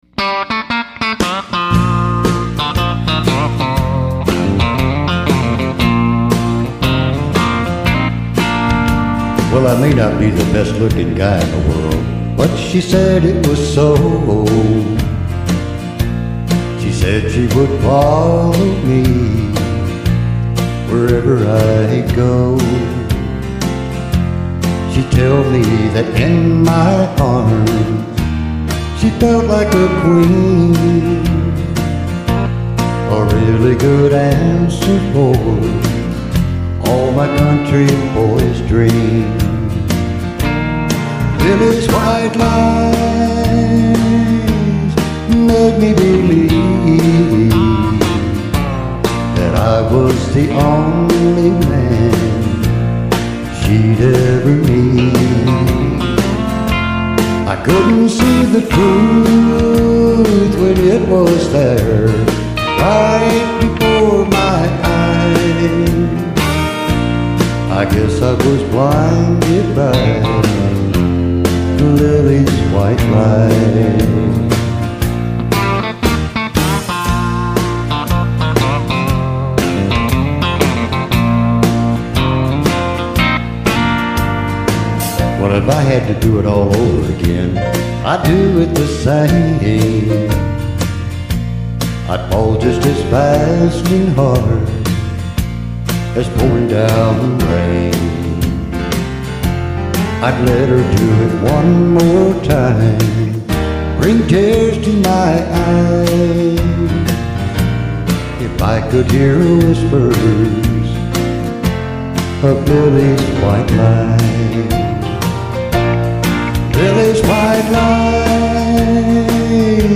Classic Country, Old Rock N' Roll, Country Gospel, and Blues